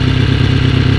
Engines